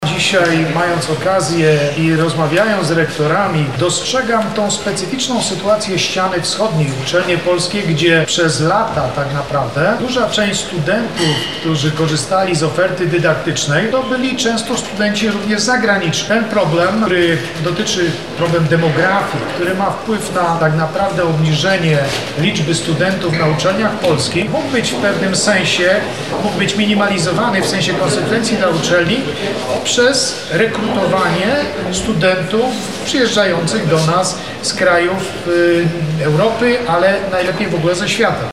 Dzisiaj (15.05) miało miejsce otwarte spotkanie pod hasłem „Porozmawiajmy o polskiej nauce”.
– mówi prof. Marek Gzik, wiceminister Nauki i Szkolnictwa Wyższego.
Wiceminister-Marek-Gzik.mp3